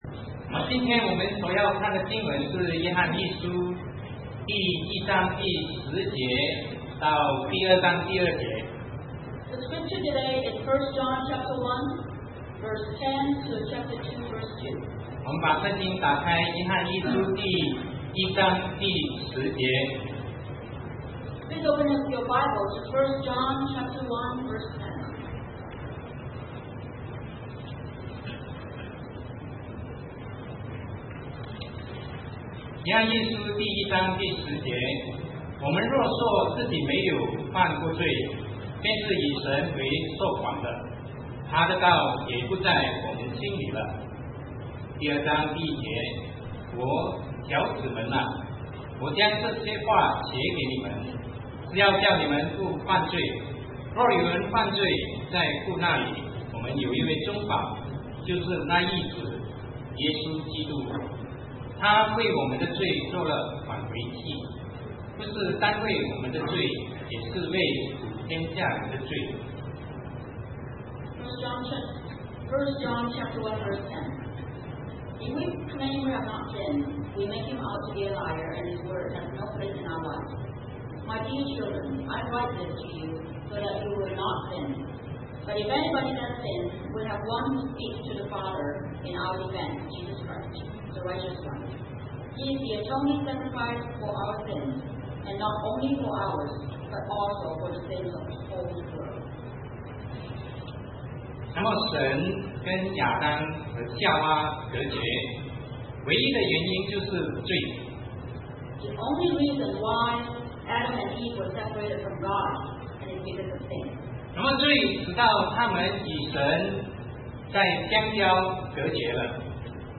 Sermon 2009-08-16 Because of Jesus We can Come Before God